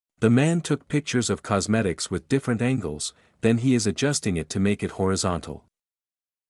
※当メディアは、別途記載のない限りアメリカ英語の発音を基本としています。
中上級/z/の発音